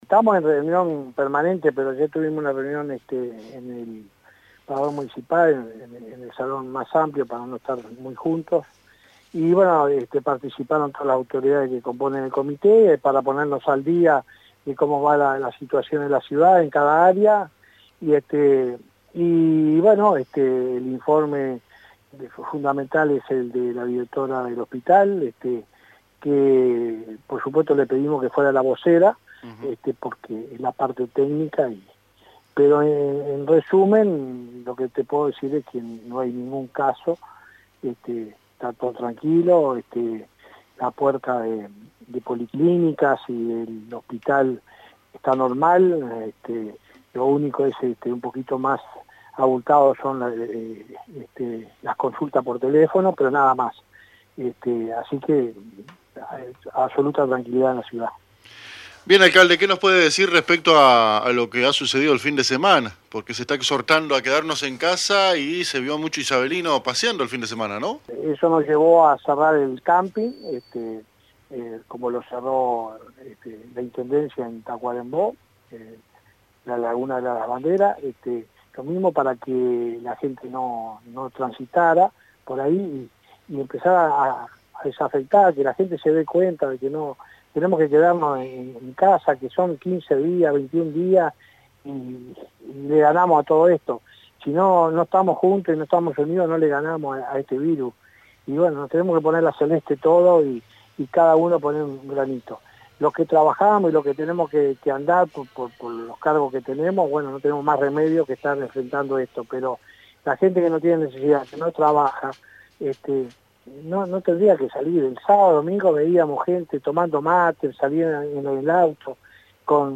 El Alcalde de Paso de los Toros fue entrevistado por a AM 1110 donde exhorta a no realizar los paseos de fin de semana, hay que quedarse en casa.